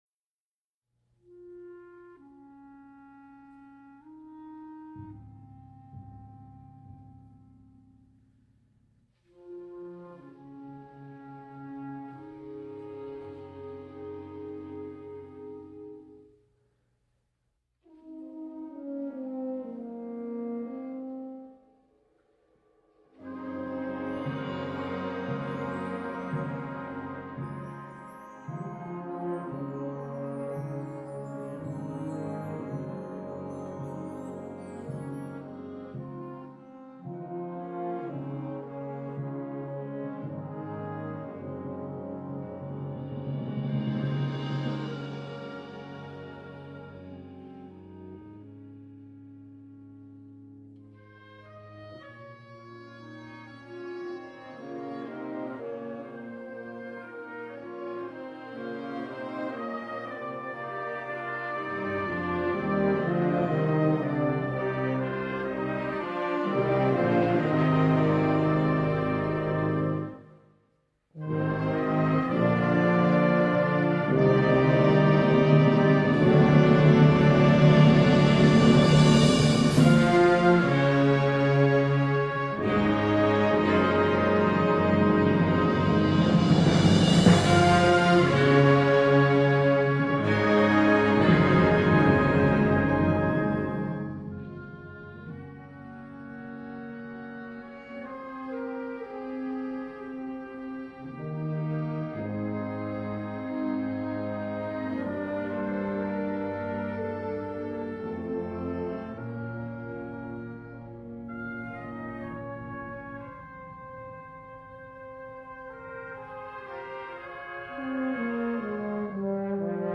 Concert Band
Powerful and ethereal.